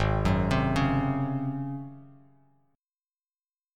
G#11 chord